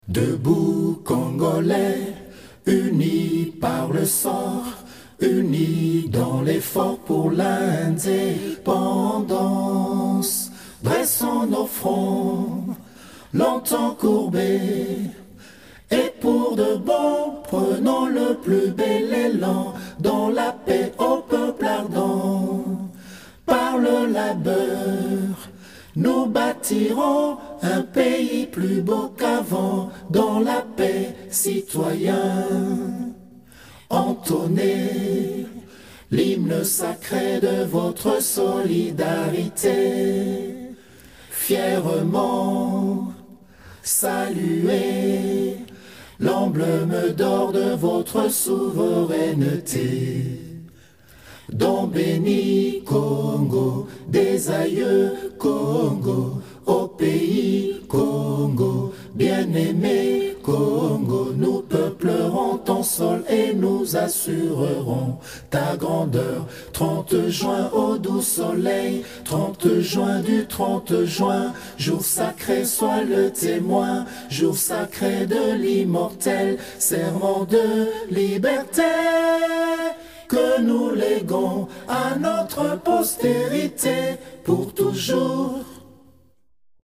accapela.mp3